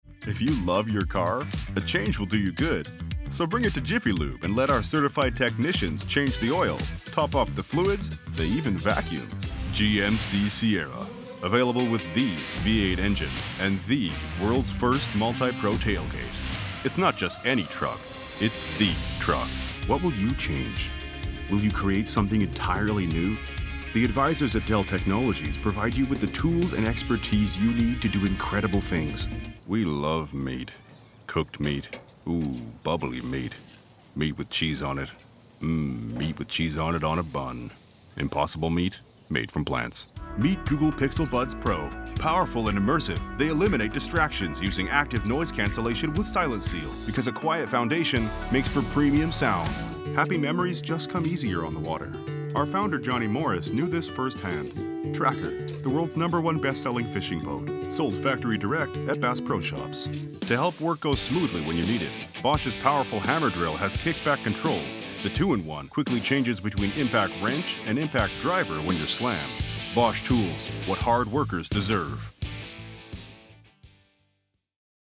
My voice is rich, reassuring, textured, and steady.
Commercial Demo
Standard American, Eastern Canadian, Southern American, New York, British, Irish, Scottish, Russian